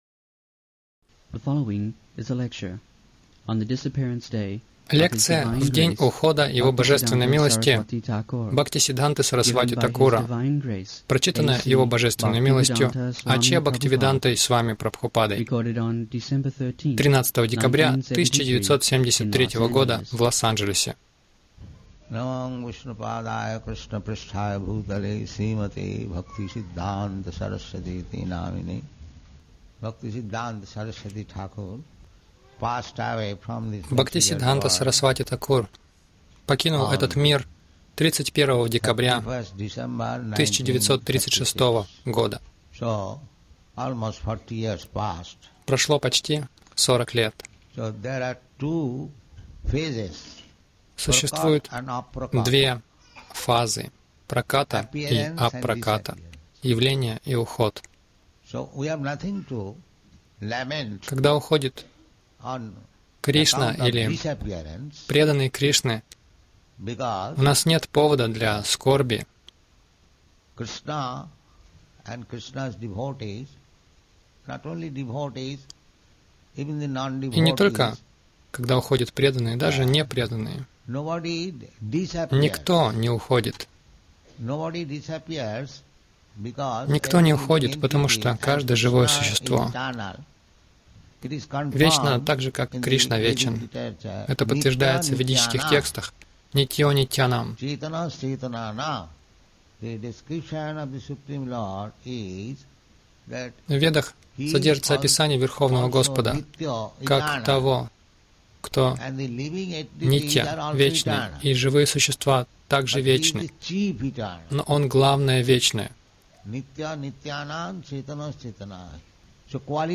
Лекция на день ухода Бхактисиддханты Сарасвати Тхакура
Милость Прабхупады Аудиолекции и книги 13.12.1973 Праздники | Лос-Анджелес Лекция на день ухода Бхактисиддханты Сарасвати Тхакура Загрузка...